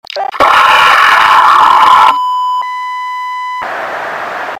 HL2 Metro Cop Death Sound Buttons
hl2-beta-metro-cop-death-sound.mp3